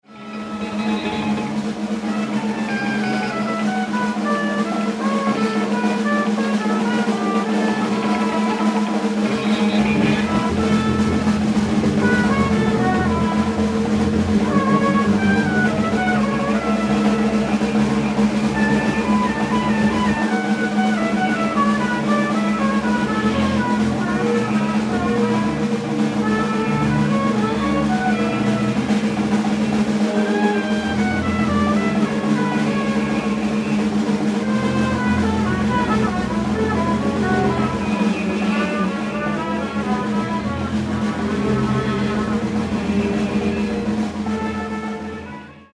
trumpet, electronics
guitar, table top guitar, and homebrew software
drums, percussion